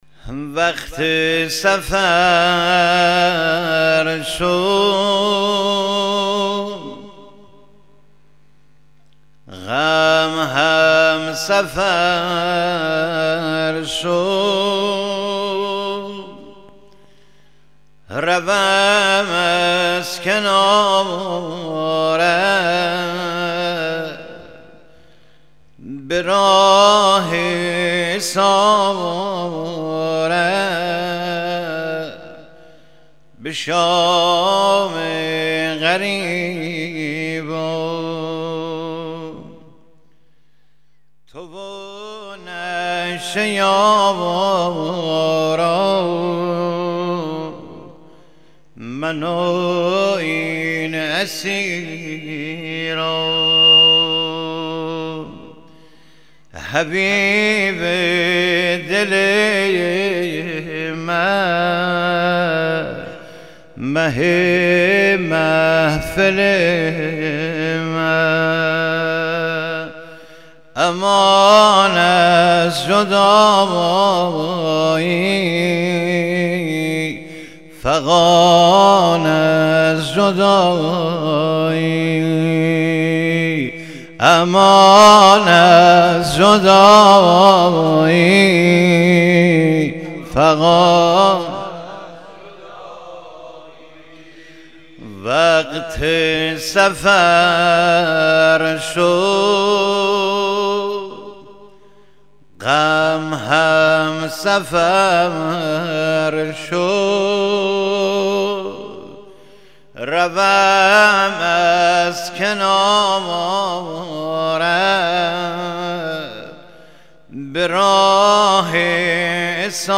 محرم و صفر 1387 ساری هیأت پرورش یافتگان مکتب عاشورا